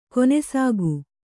♪ konesāgu